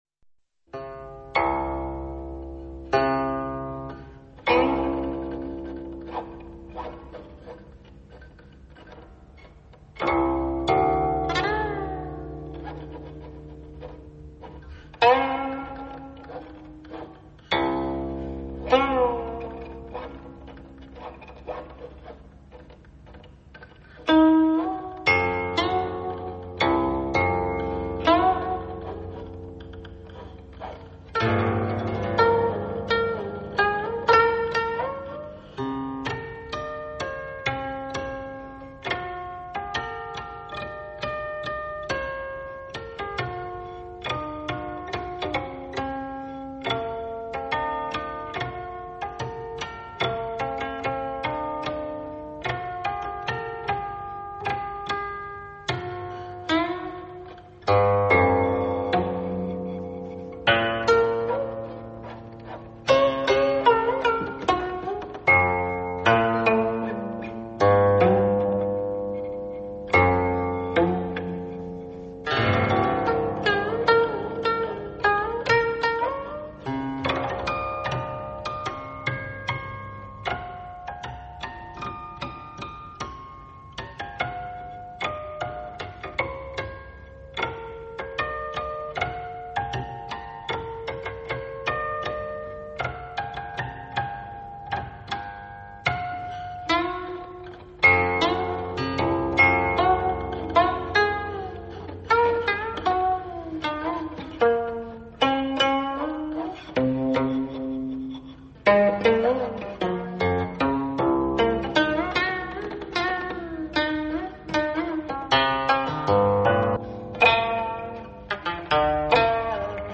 民族音乐
Solo of Ancient Qin
（古琴独奏）